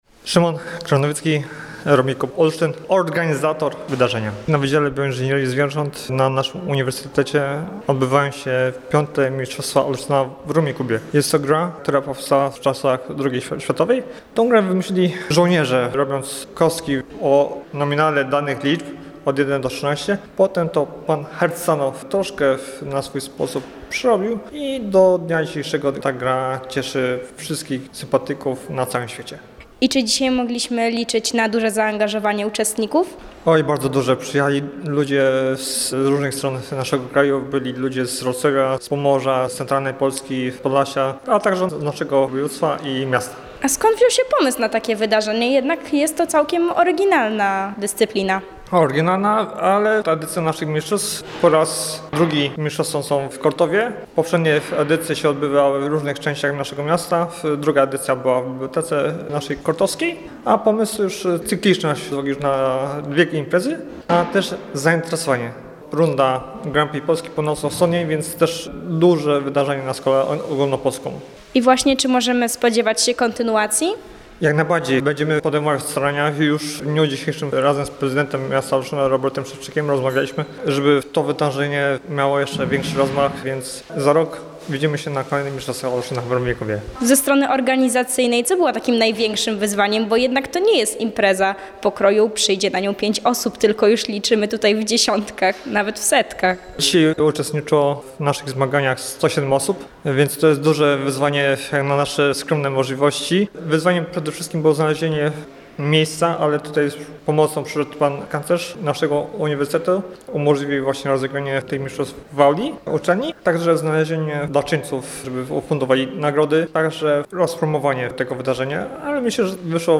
podkreślał w rozmowie z naszą reporterką jeden z organizatorów mistrzostw